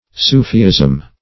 Soofeeism \Soo"fee*ism\
soofeeism.mp3